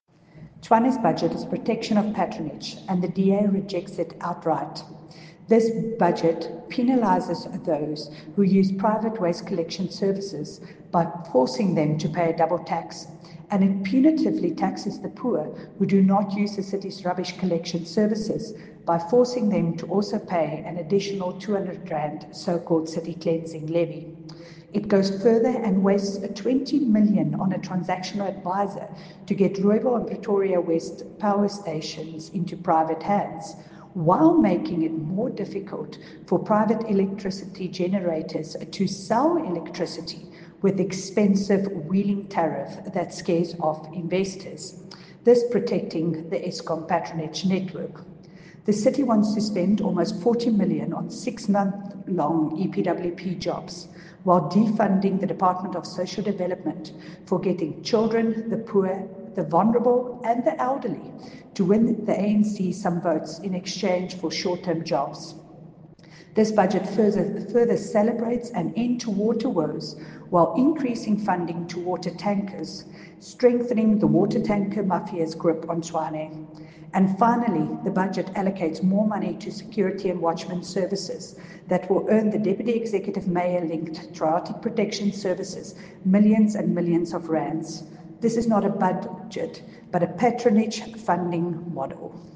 Note to Editors: Please find an English soundbite by Cllr Jacqui Uys
Jacqui-Uys_ENG_DA-rejects-Tshwanes-budget.mp3